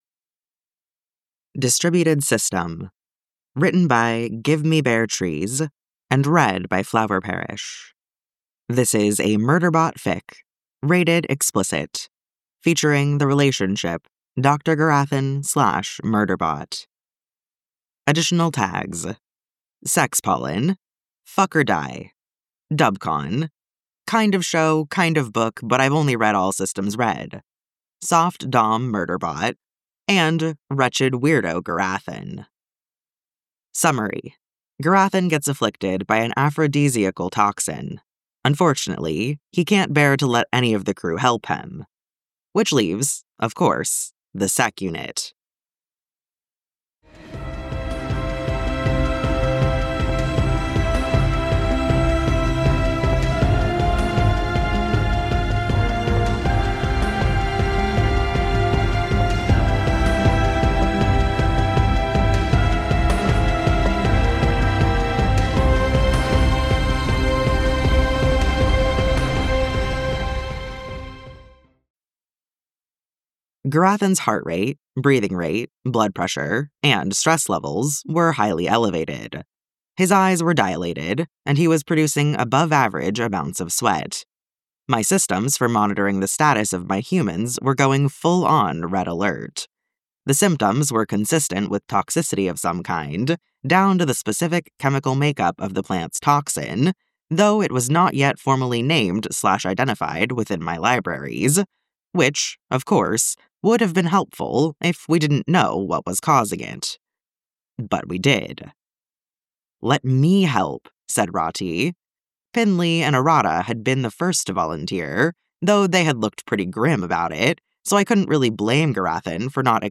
music and effects download mp3: here (r-click or press, and 'save link') [22 MB, 00:23:20]